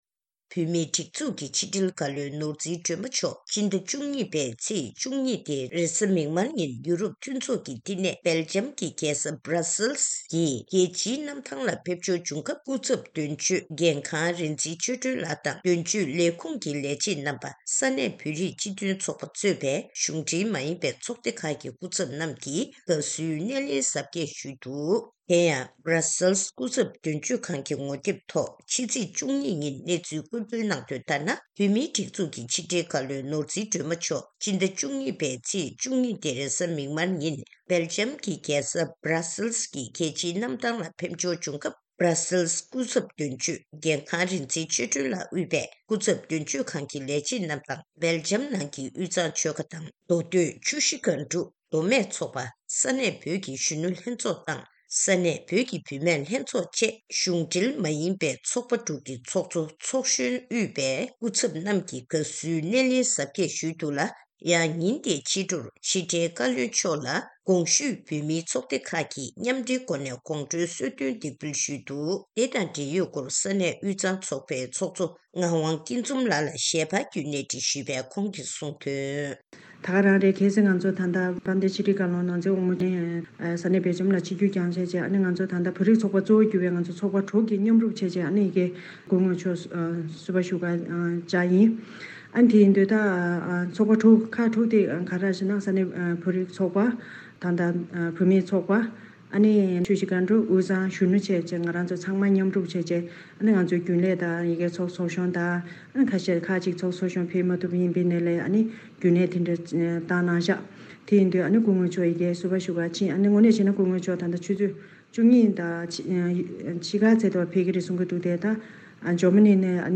ཞལ་པར་བརྒྱུད་གནས་འདྲི་ཞུས་པ་ཞིག་གསན་རོགས་ཞུ།།